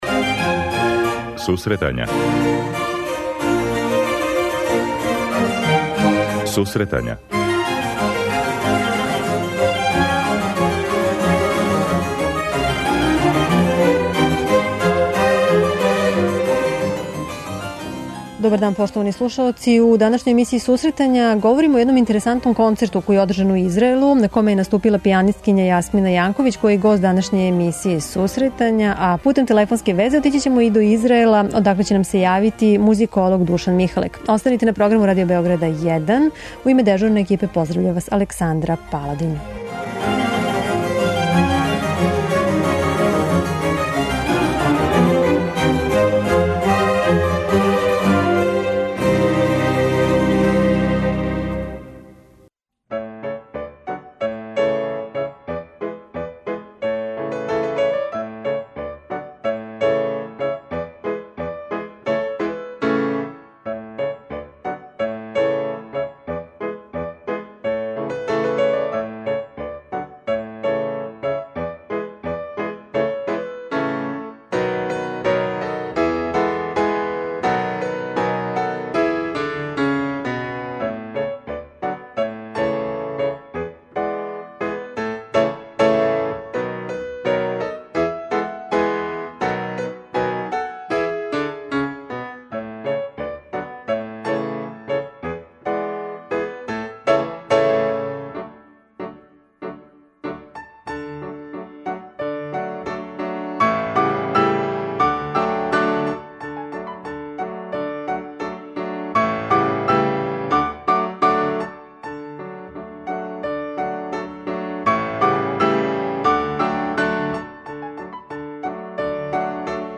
На телефонској вези биће и музиколог